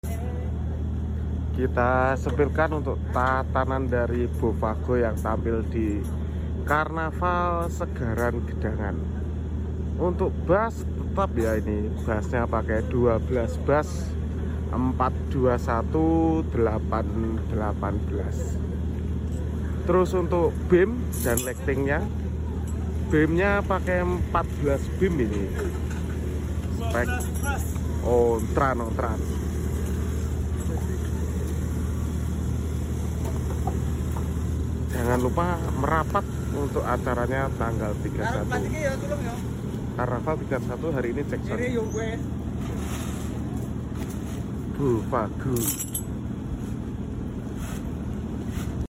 Bofago 14 beem karnaval segaran